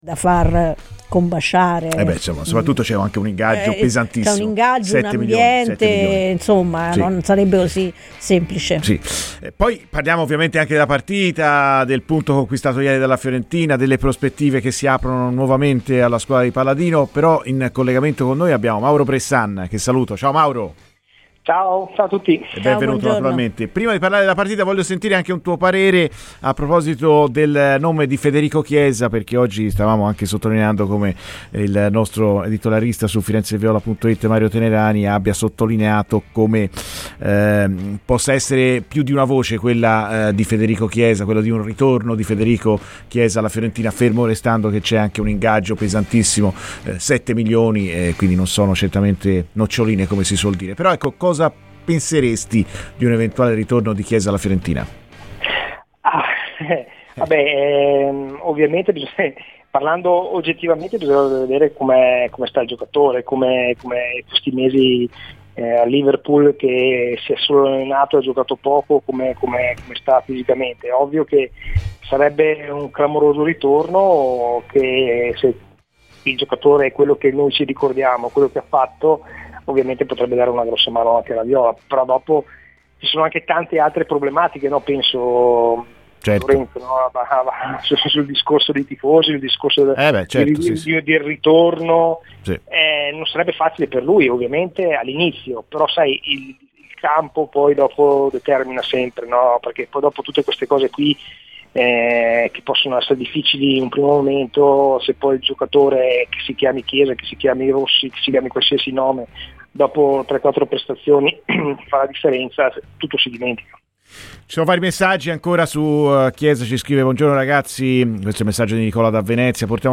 ASCOLTA IL PODCAST PER L'INTERVISTA COMPLETA Mauro Bressan a Radio FirenzeViola